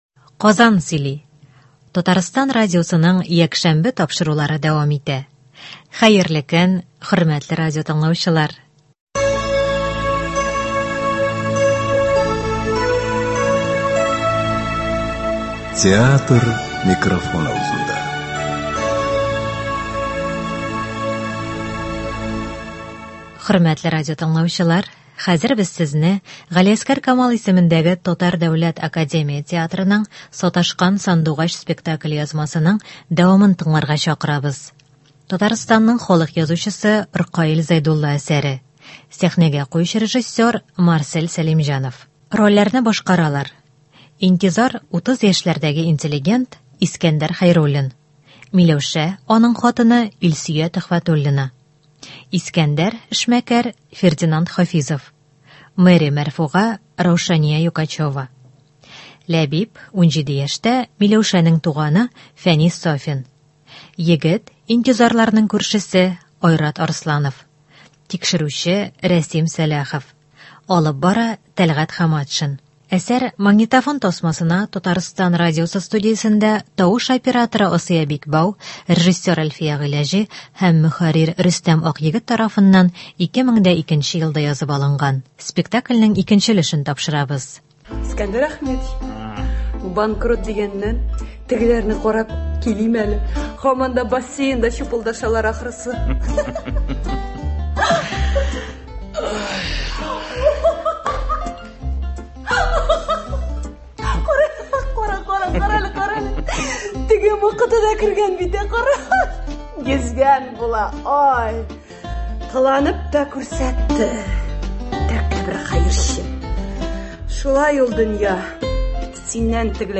Г. Камал ис. ТДАТ спектакленең радиоварианты. 1 өлеш.